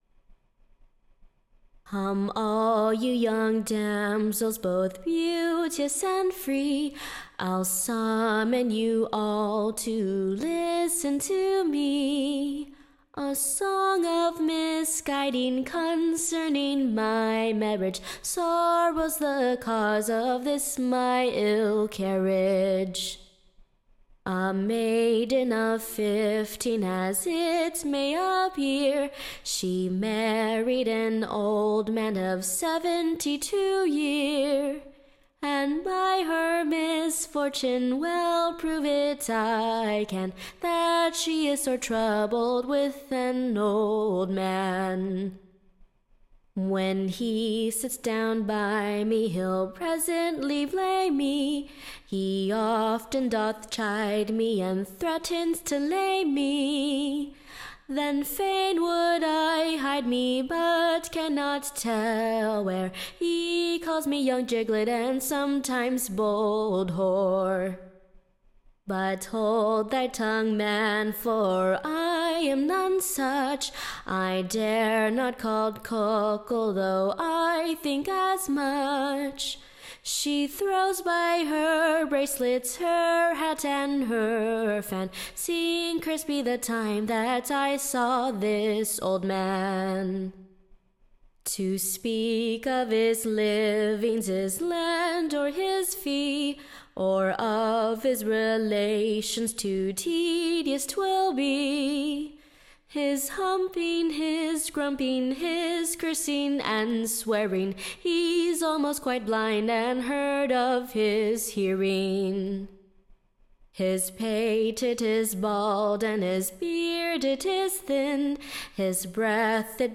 Recording Information Ballad Title The Young-womans Complaint: / OR, / A Caveat to all Maids to have a care how they be / Married to Old Men.